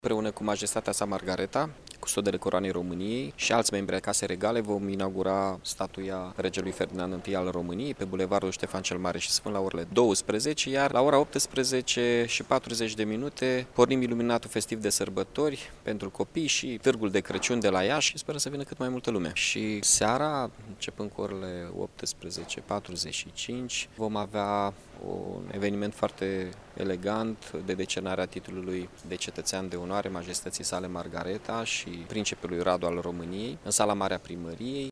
Primarul Mihai Chirica a anunţat că seria evenimentelor va începe pe 30 noiembrie la ora 12,00, în prezenţa reprezentanţilor Casei Regale, urmând ca seara să fie deschis Târgul de Crăciun.